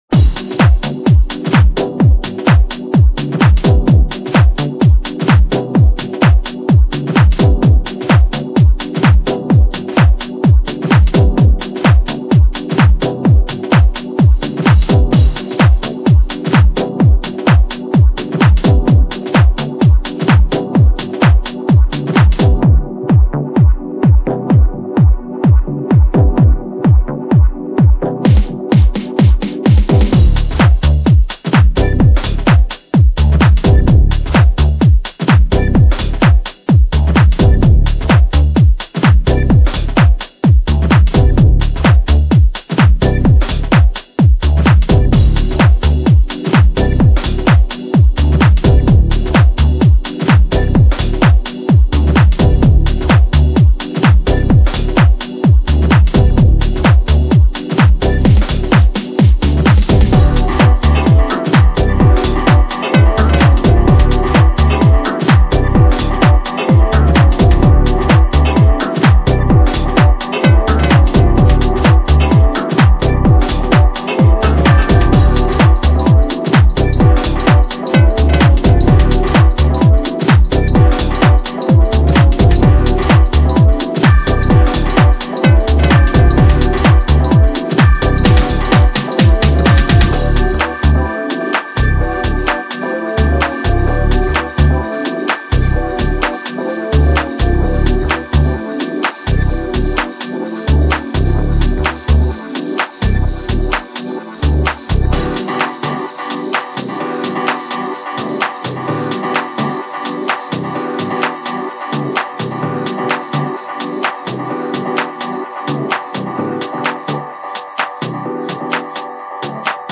vibrant synths and buoyant house rhythms